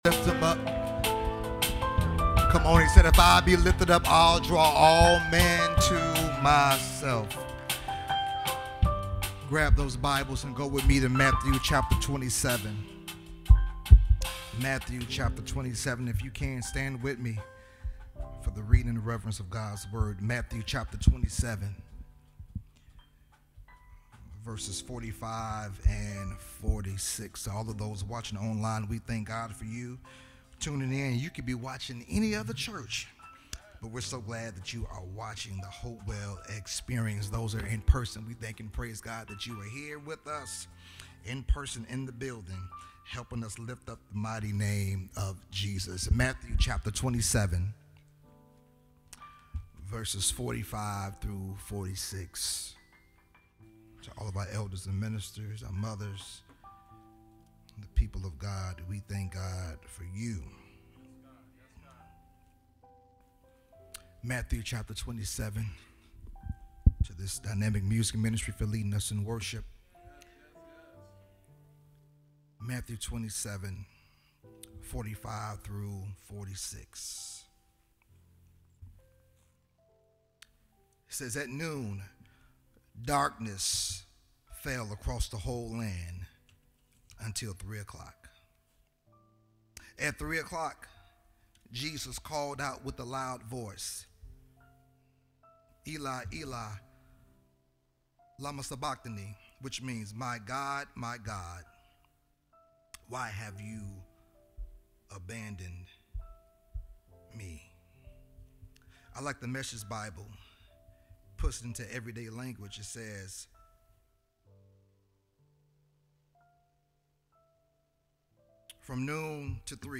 Hopewell Missionary Baptist Church, Carbondale IL
audio sermon